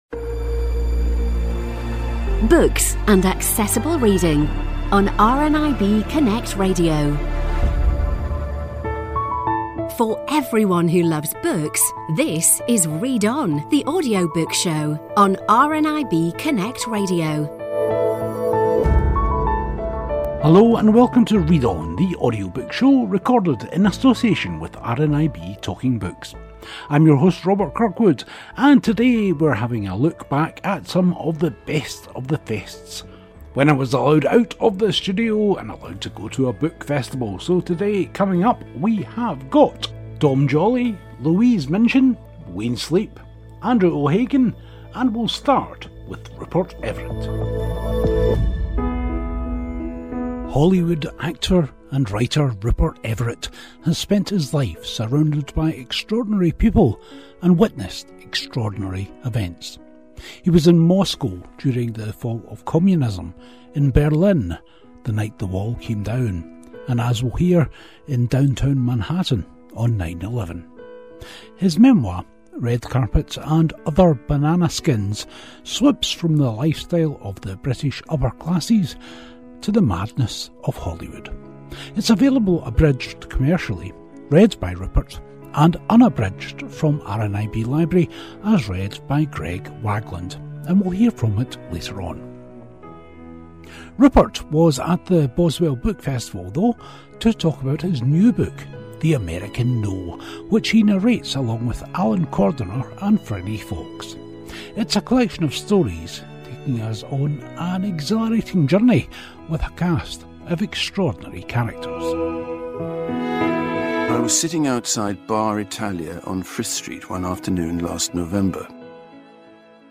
A weekly show all about audiobooks recorded at the RNIB Talking Book studios. We talk to your favourite authors and narrators, along with reviews and news about new audiobooks.